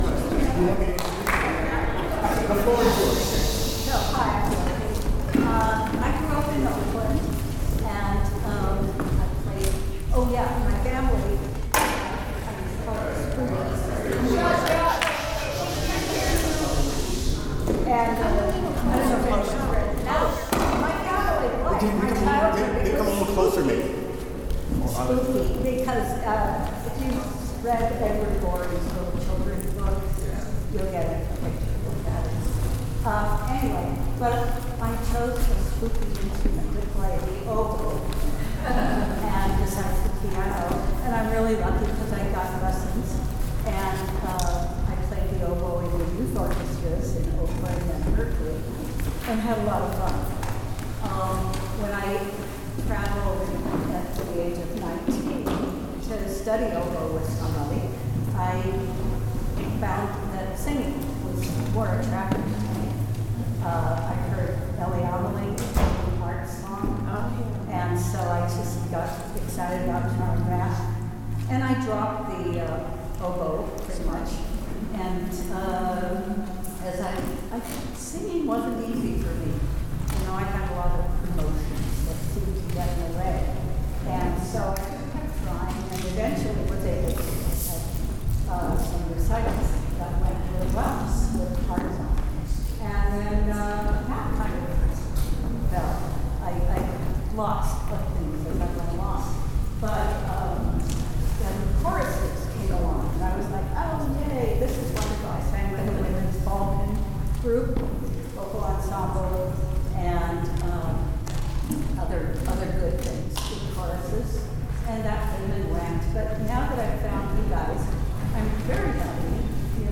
OSC Rehearsal, April 30, 2025
Vocal warm-ups